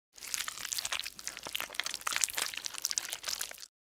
Download Free Cartoon Slime Sound Effects | Gfx Sounds
Experience the squishy, sticky, and disgustingly satisfying sounds of Slime!
Cartoon-slime-bubbling-wet-gloop-movement.mp3